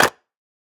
Minecraft Version Minecraft Version 25w18a Latest Release | Latest Snapshot 25w18a / assets / minecraft / sounds / block / mud_bricks / step1.ogg Compare With Compare With Latest Release | Latest Snapshot
step1.ogg